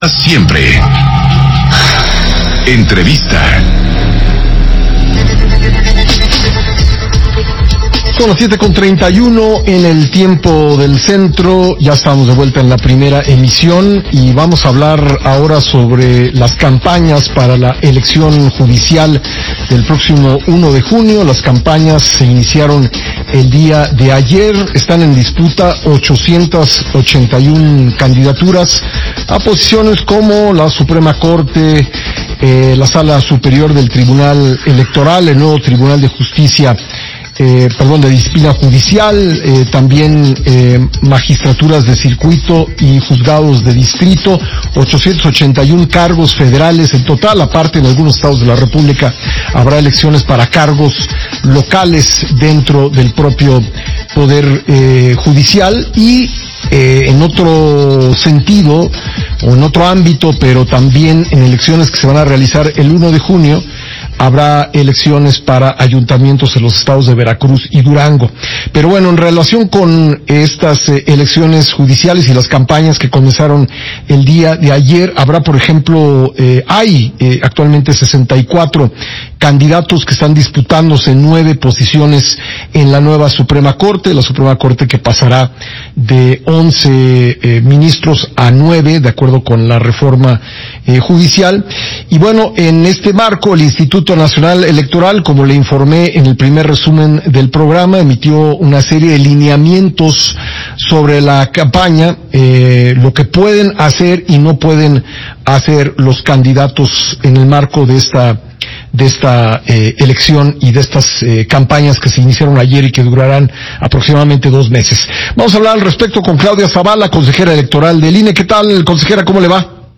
Entrevista de la Consejera Electoral Claudia Zavala con Pascal Beltrán del Río Para Grupo Imagen - Central Electoral